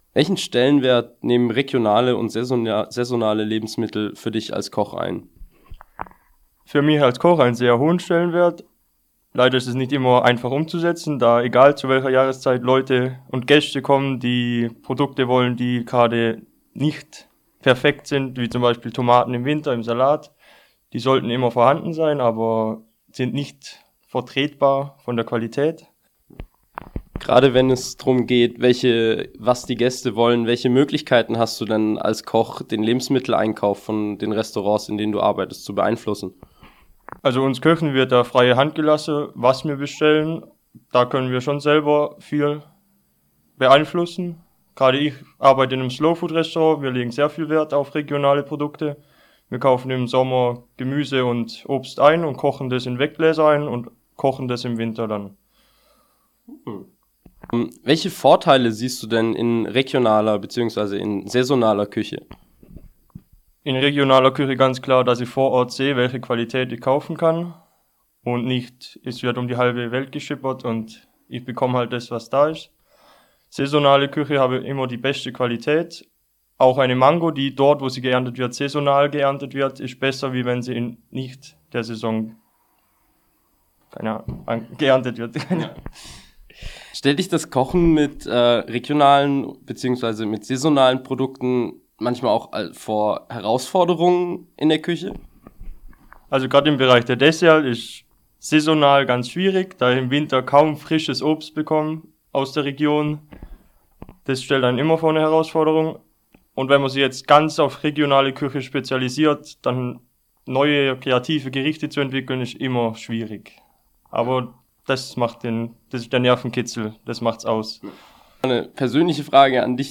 Dieser Eintrag wurde veröffentlicht unter Interview Ernährung Umwelt On Air und verschlagwortet mit Ökologie & Nachhaltigkeit deutsch am von